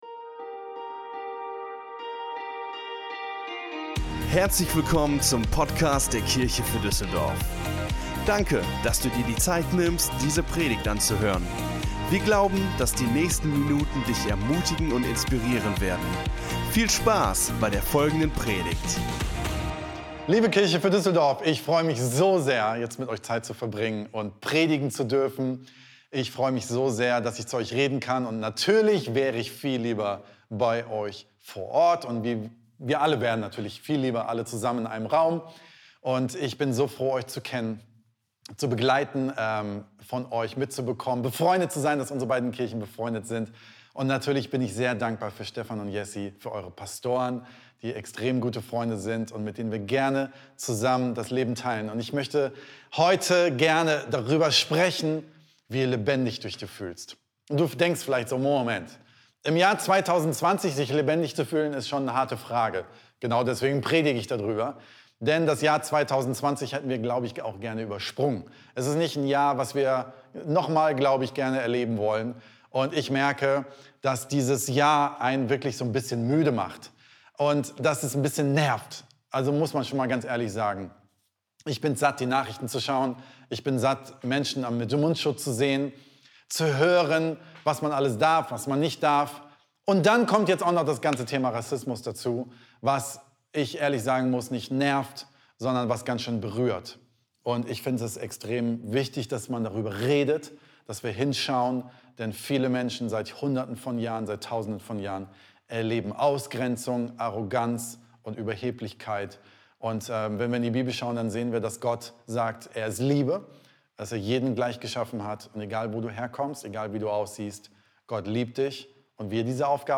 Gastsprecher aus der Kirche im Pott